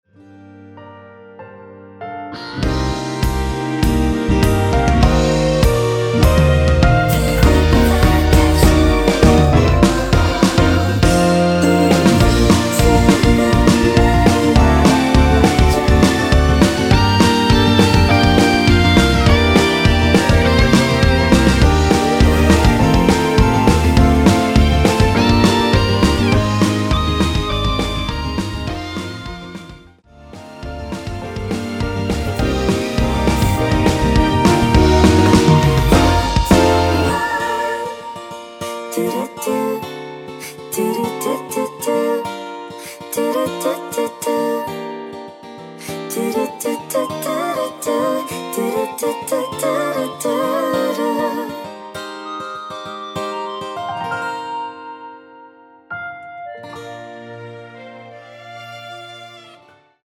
원키에서(+4)올린 멜로디와 코러스 포함된 MR입니다.(미리듣기 확인)
Bb
앞부분30초, 뒷부분30초씩 편집해서 올려 드리고 있습니다.